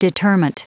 Prononciation du mot determent en anglais (fichier audio)
Prononciation du mot : determent